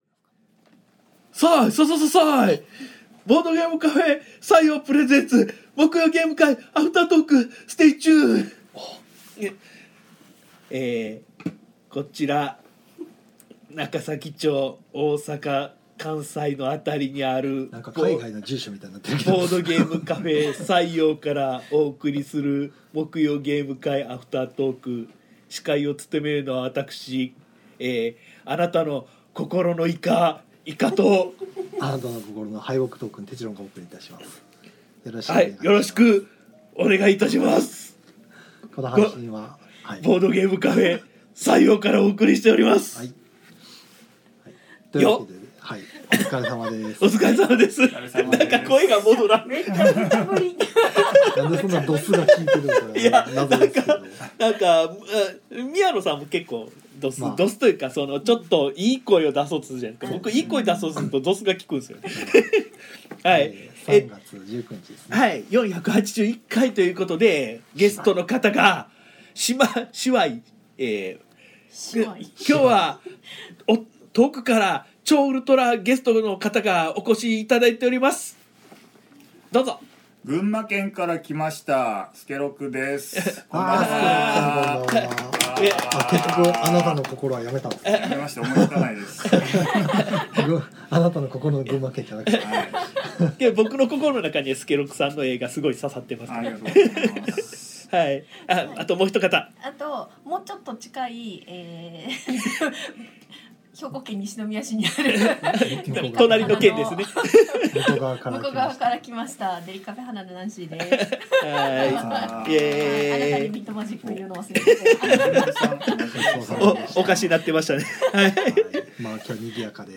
ゲーム会の話や、近況などをダラダラと生配信で垂れ流したものを鮮度そのままノーカットでパッケージング！（podcast化）